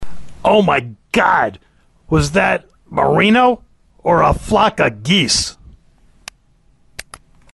geese.mp3